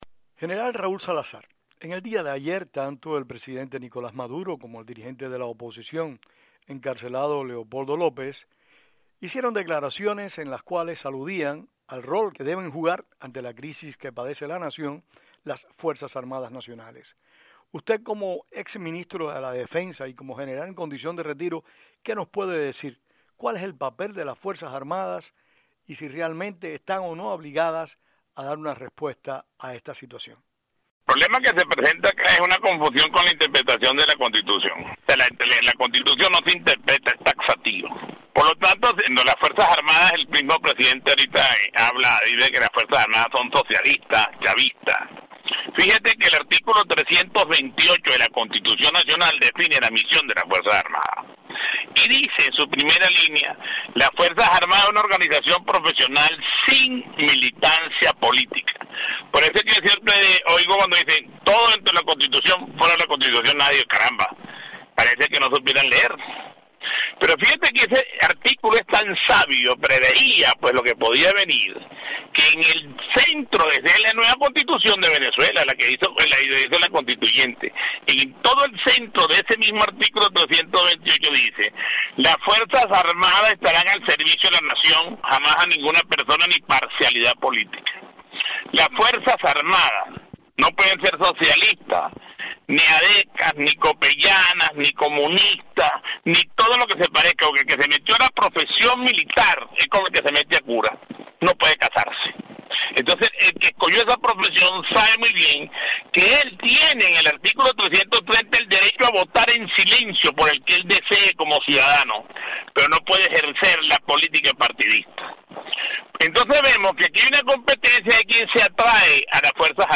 El General Salazar dijo en una entrevista telefónica desde Caracas que de acuerdo con la Constitución, las Fuerzas Armadas no deben tener parcialidad política como organización, aunque la misma Constitución les concede a sus integrantes, el derecho al voto en silencio como ciudadanos.
Entrevista a General Raúl Salazar